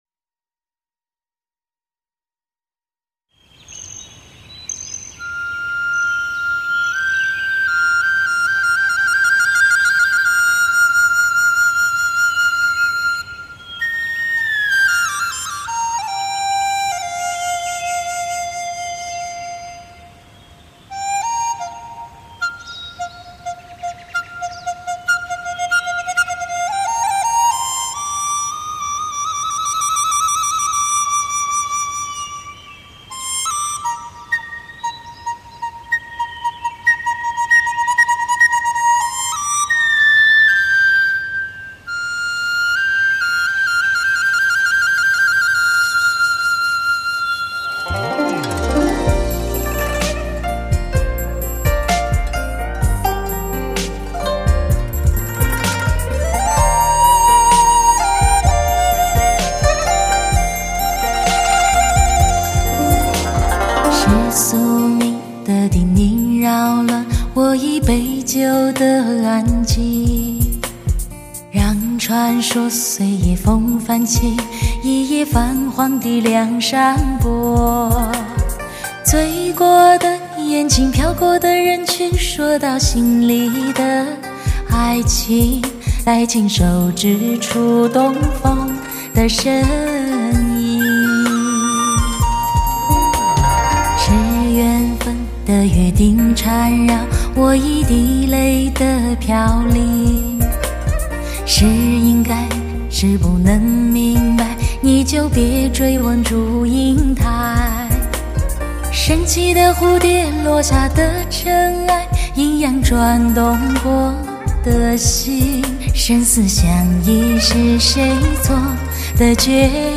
无论任何方面都更多的考虑了专业室内试听空间和车载试听空间的区别，在MIX制作时考虑了两者因素的融合，适合更多的试听环境！